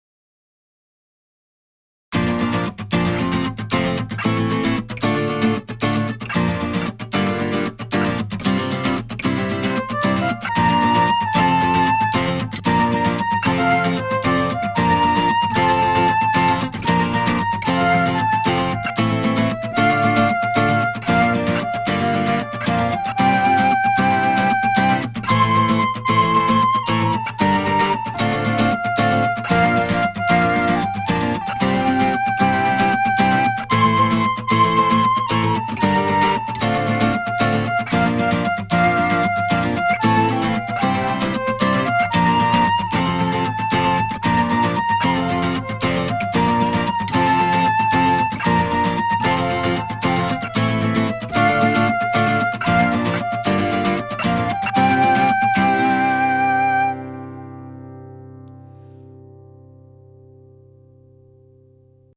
InstrumentalCOUPLET/REFRAIN